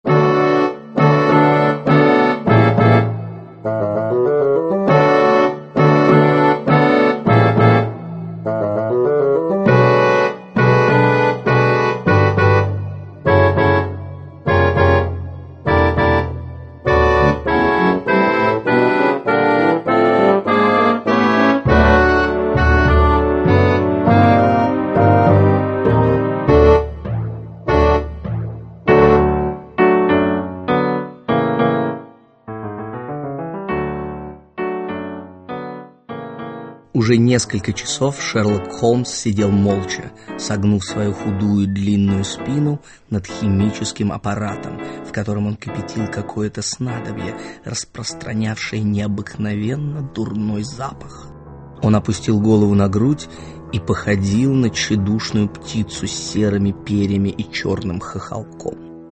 Аудиокнига Пляшущие фигурки. Аудиоспектакль | Библиотека аудиокниг